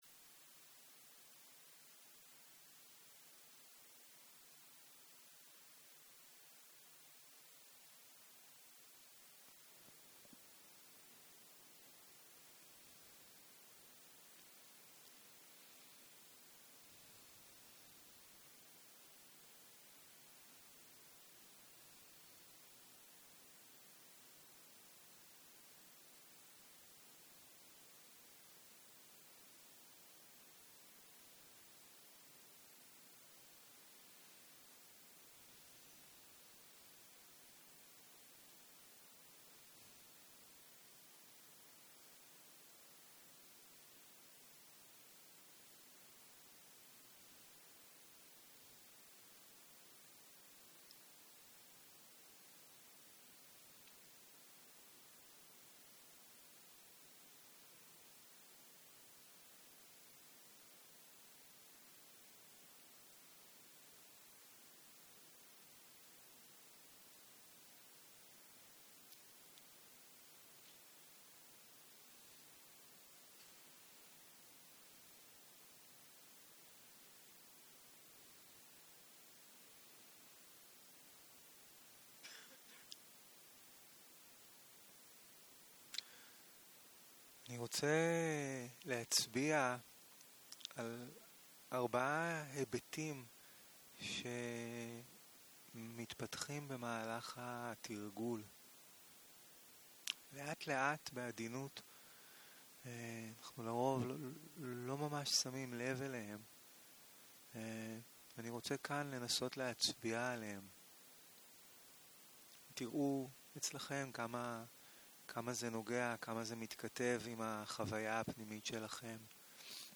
31.03.2023 - יום 3 - בוקר - הנחיות מדיטציה - הקלטה 5
Guided meditation שפת ההקלטה